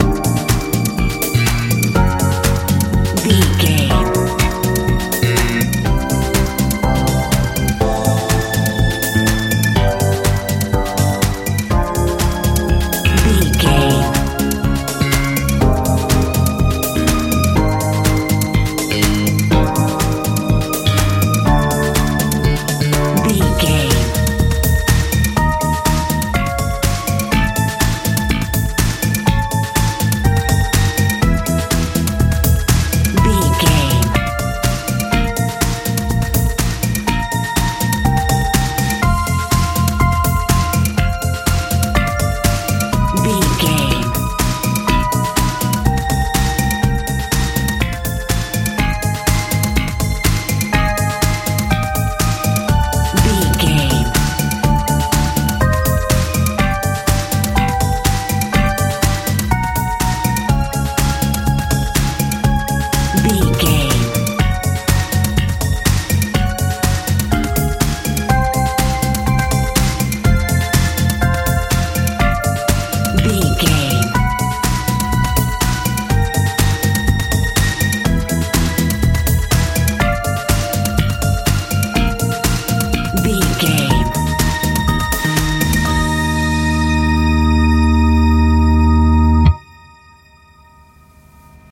jpop dance feel
Ionian/Major
D
dreamy
sweet
synthesiser
bass guitar
drums
80s
90s
soothing
soft